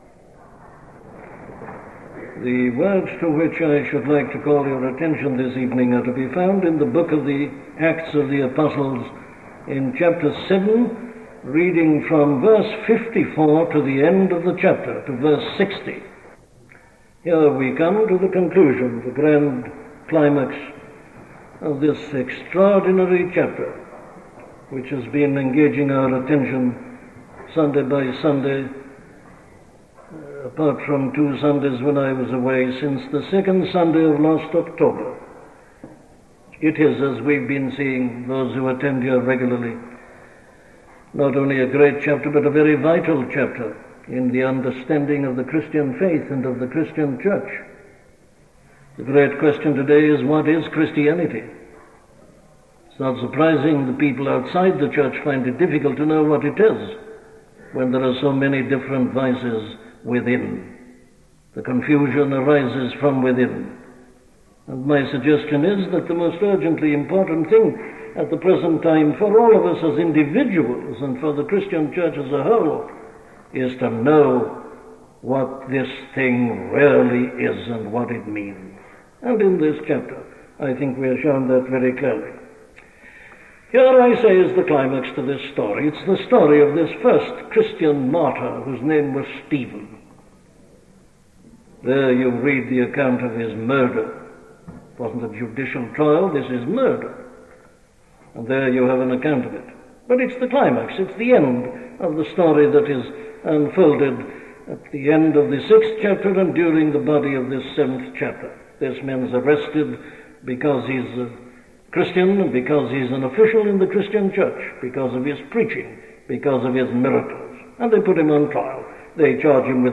The Waiting Lord - a sermon from Dr. Martyn Lloyd Jones